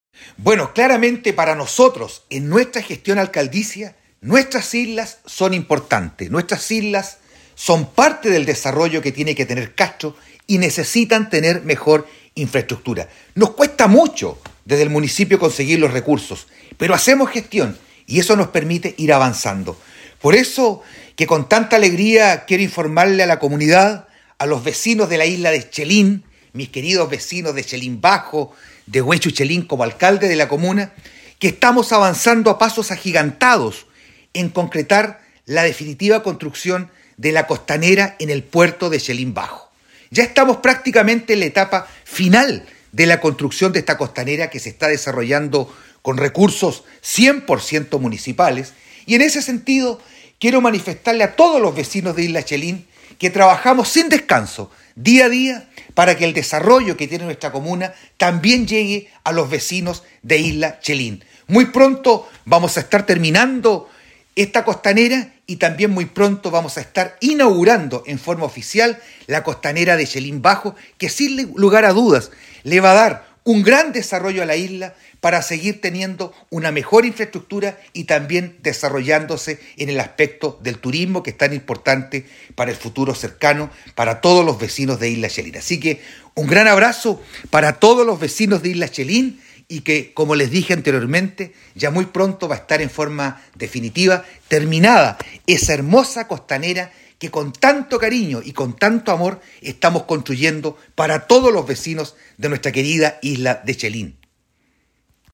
ALCALDE-VERA-COSTANERA-CHELIN.mp3